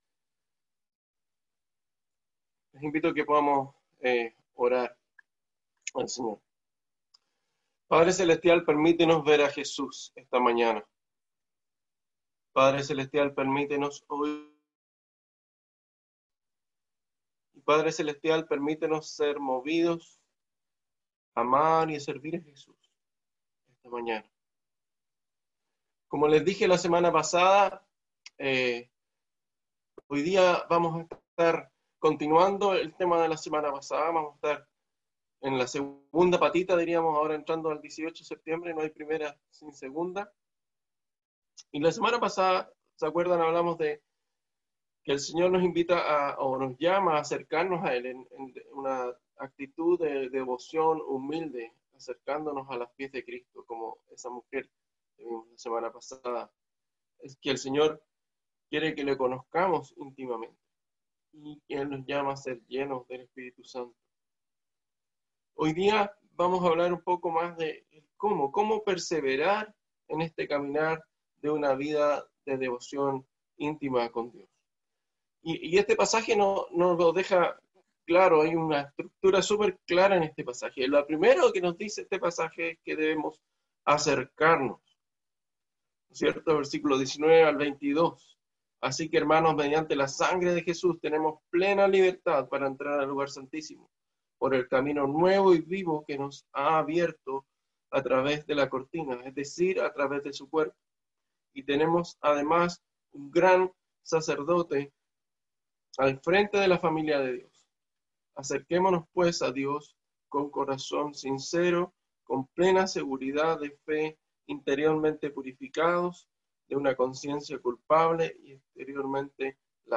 Sermones
Website de la Iglesia St. James de Punta Arenas Chile